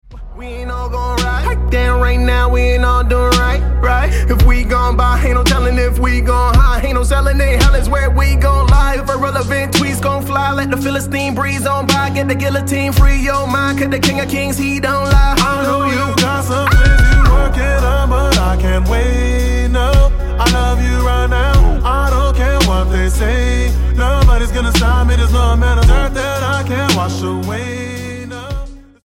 Canadian rapper
Style: Hip-Hop